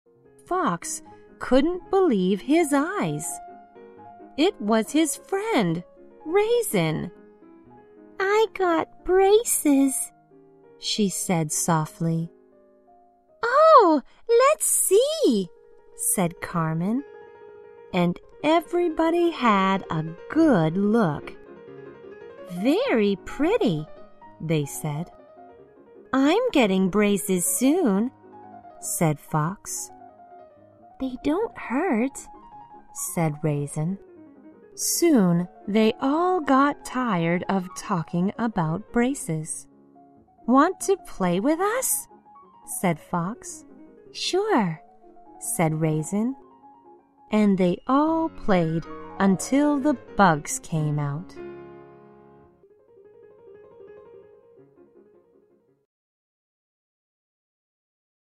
在线英语听力室小狐外传 第28期:小葡萄的听力文件下载,《小狐外传》是双语有声读物下面的子栏目，非常适合英语学习爱好者进行细心品读。故事内容讲述了一个小男生在学校、家庭里的各种角色转换以及生活中的趣事。